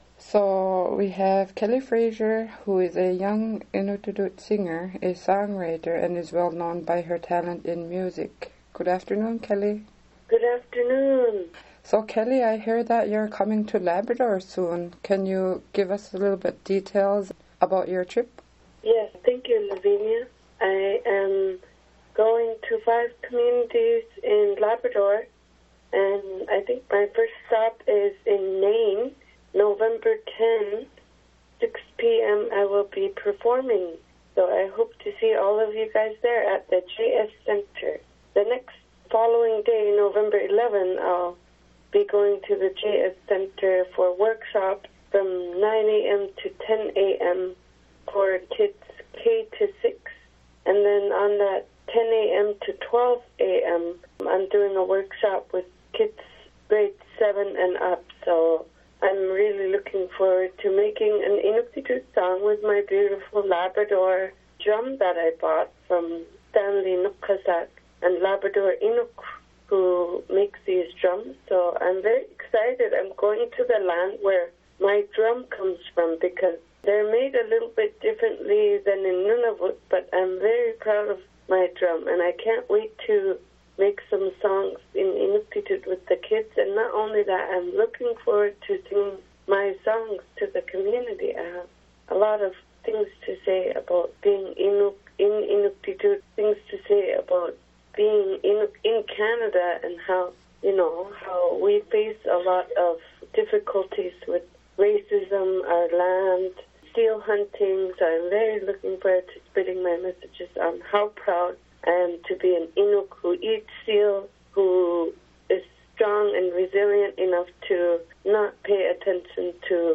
We spoke with Fraser about her visit to Labrador.